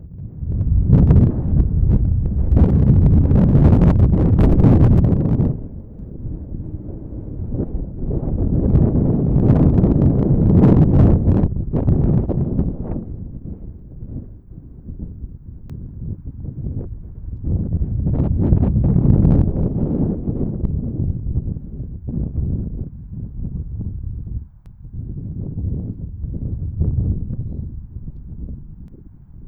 tenkoku_windturbulence_high.wav